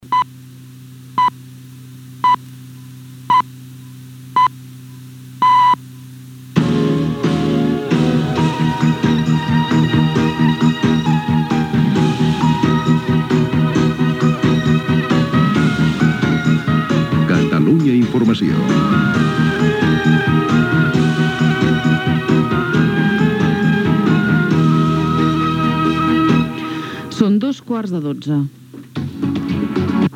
Indicatiu de l'emissora i hora